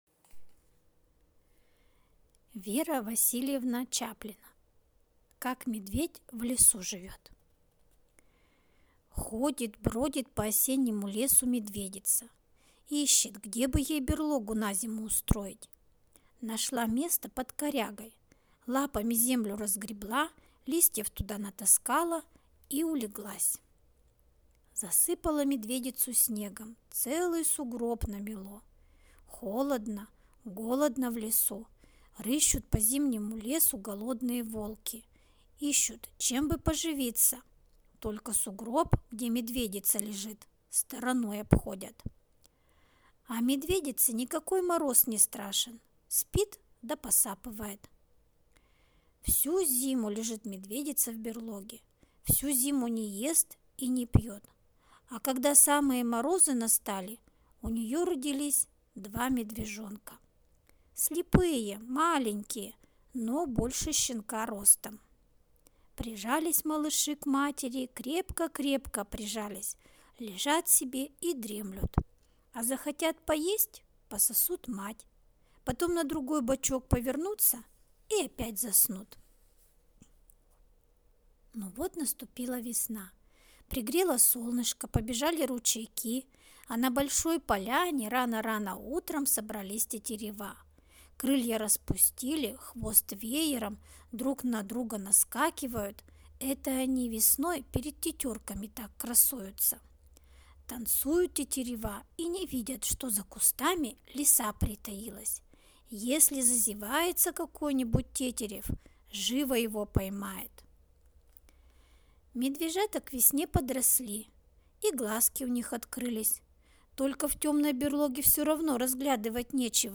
Аудиорассказ «Как медведь в лесу живет»